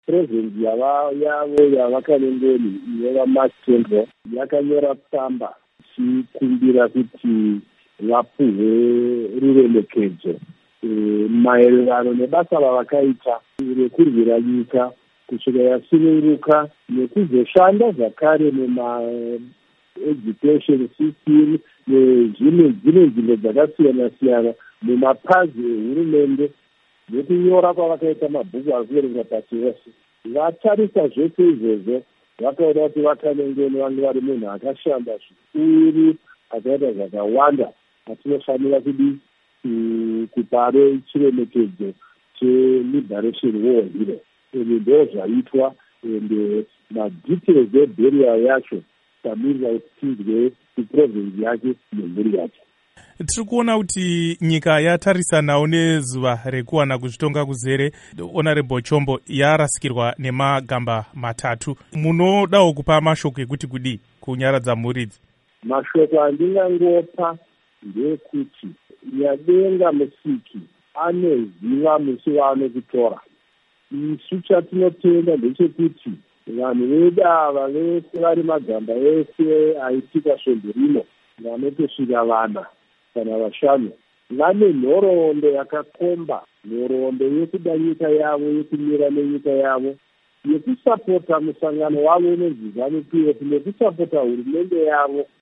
Hurukuro naVaIgnatious Chombo1